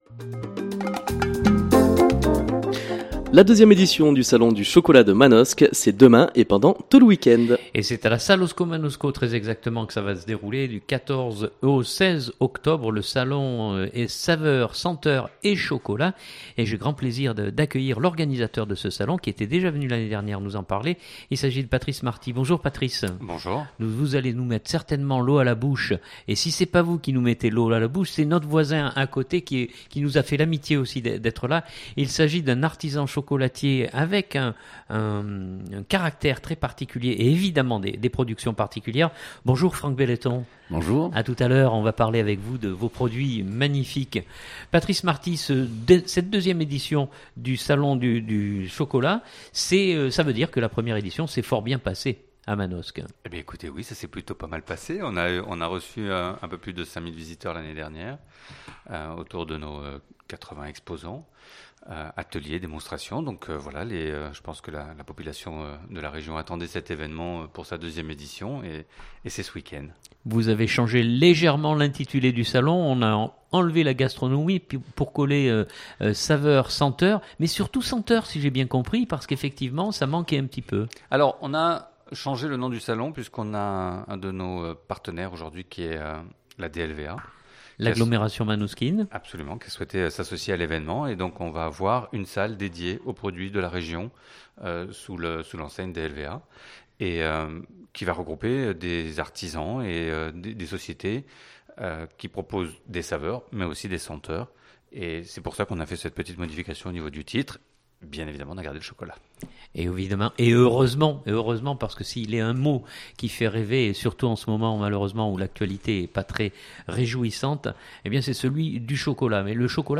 Interviewés